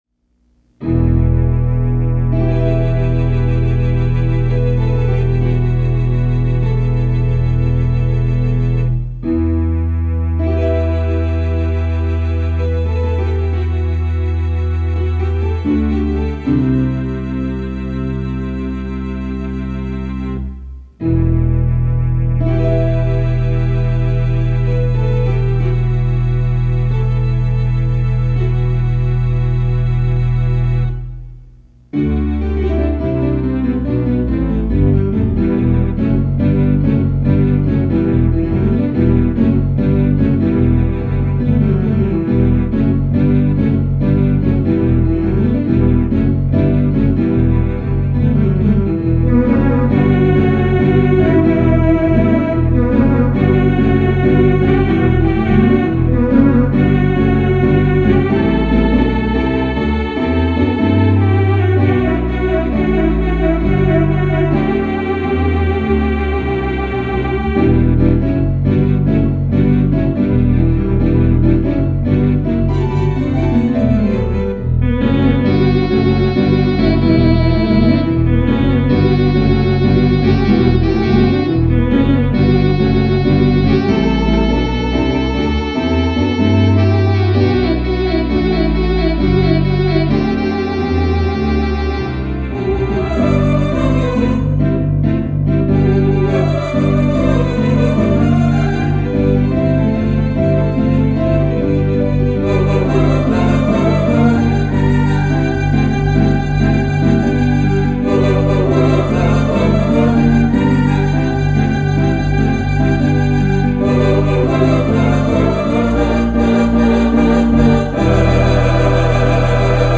VIRTUAL THEATER ORGAN MUSIC
Classic Rock